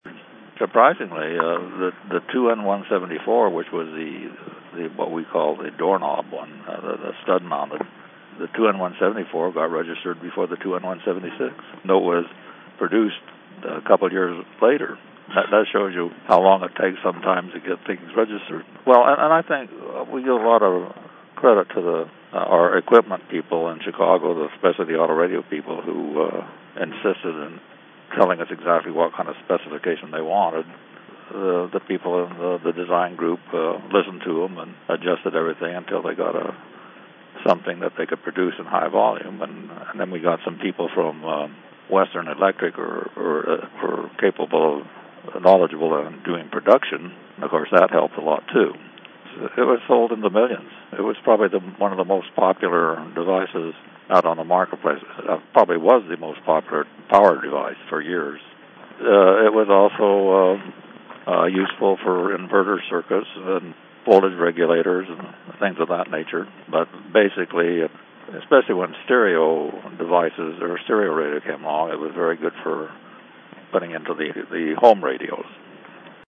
from a 2008 Interview with